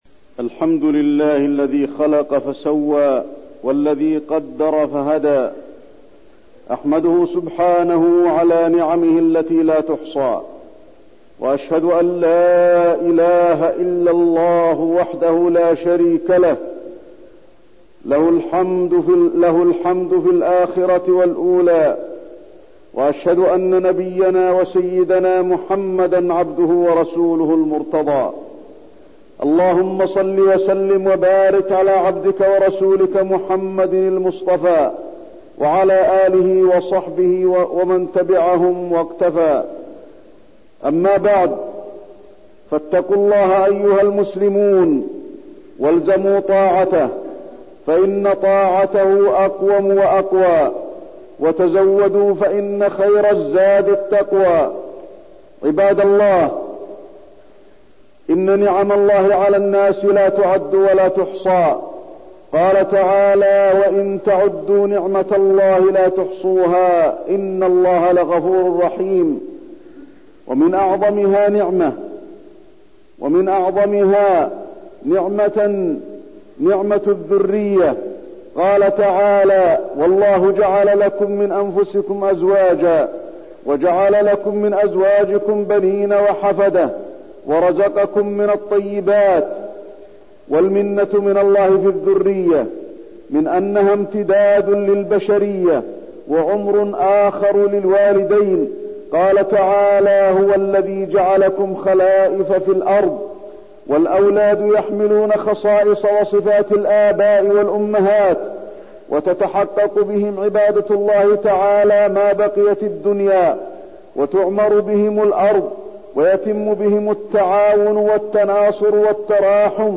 تاريخ النشر ١٨ جمادى الآخرة ١٤١١ هـ المكان: المسجد النبوي الشيخ: فضيلة الشيخ د. علي بن عبدالرحمن الحذيفي فضيلة الشيخ د. علي بن عبدالرحمن الحذيفي نعمة الأولاد وتربيتهم